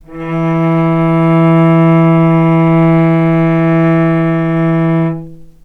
vc-E3-mf.AIF